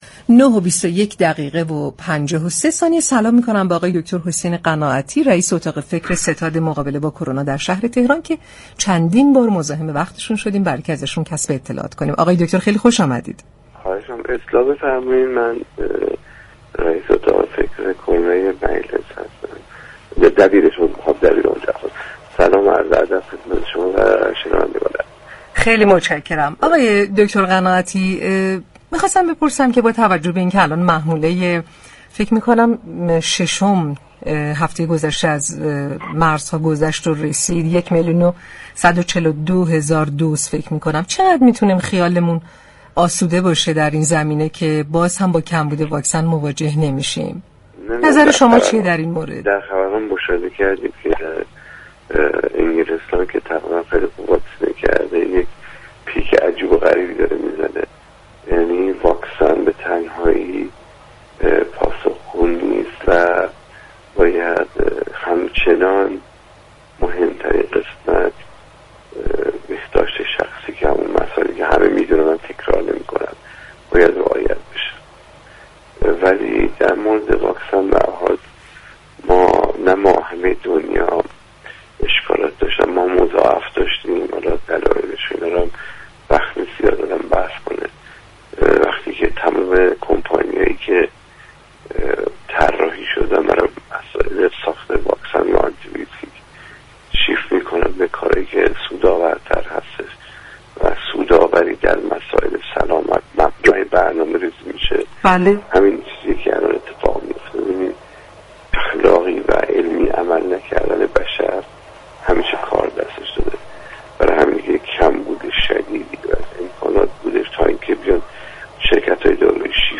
رویداد
در گفت‌وگو با برنامه تهران ما سلامت